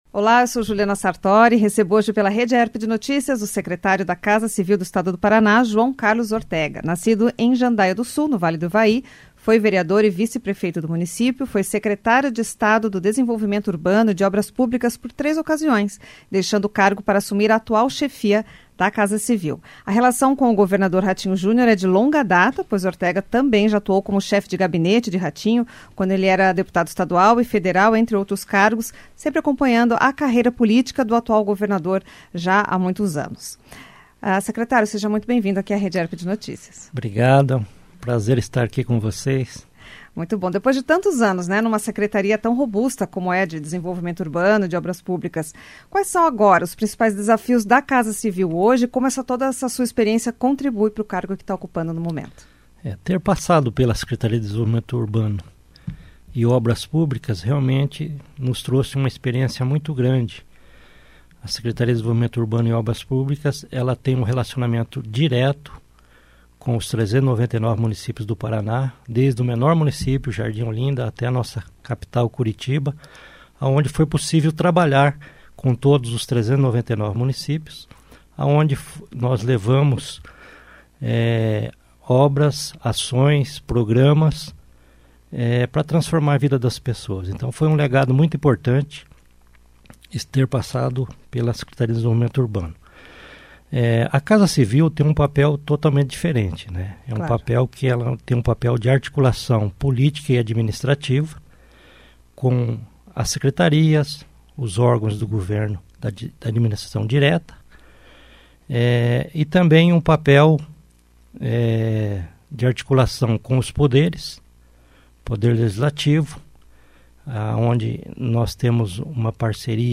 Rede Aerp de Notícias recebe scretário de Estado da Casa Civil do Paraná, João Carlos Ortega.